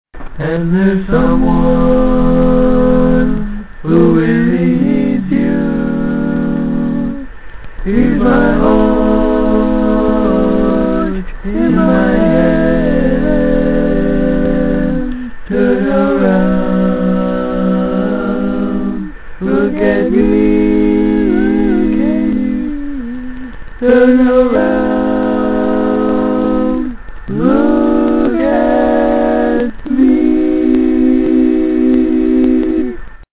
Key written in: C Major
How many parts: 4
Type: Other male
All Parts mix: